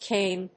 読み方 キログラムメートル